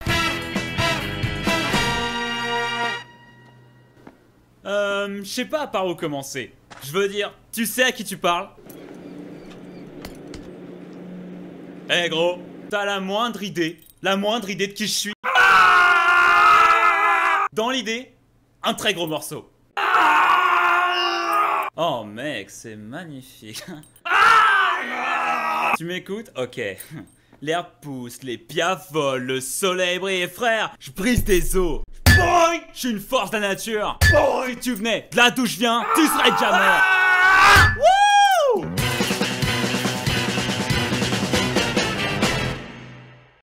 Extrait Dessin Animé (My Hero Academia)
12 - 30 ans - Baryton Ténor